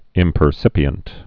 (ĭmpər-sĭpē-ənt)